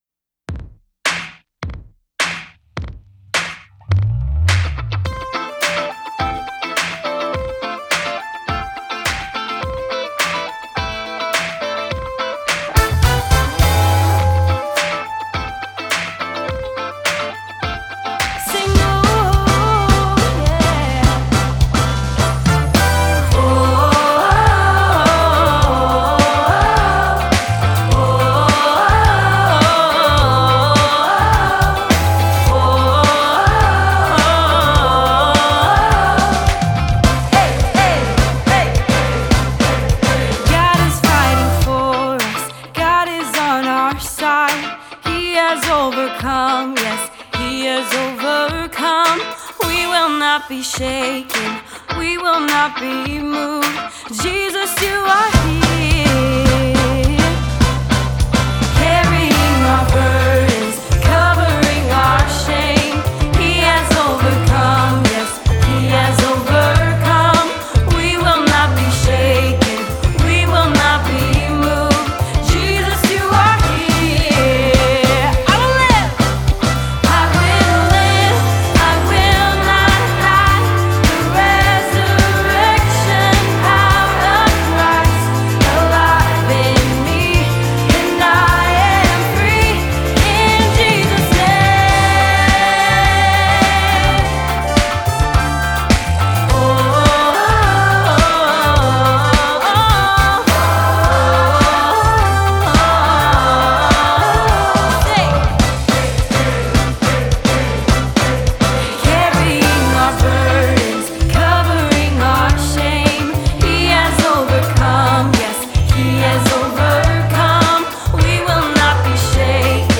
This audio was recorded from the 2020 Wheaton School.